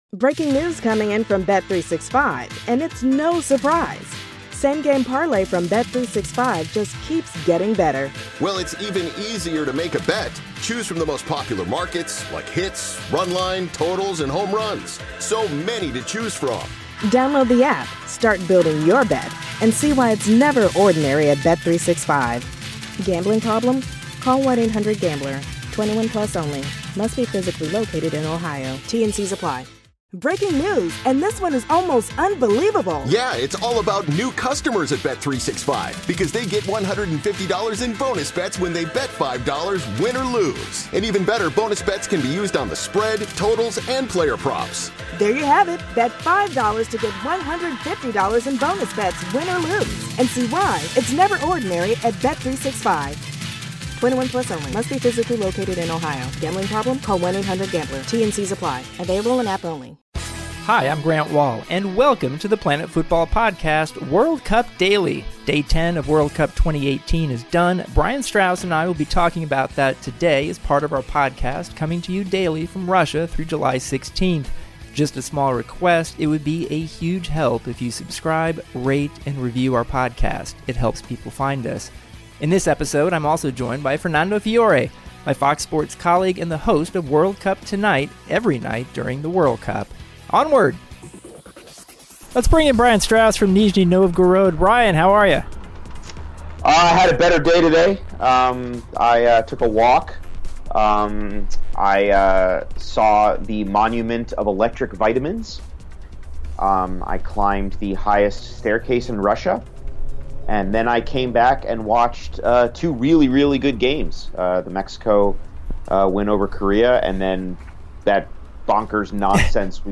Toni Kroos Saves Germany, Mexico Wins Again and Fernando Fiore Interview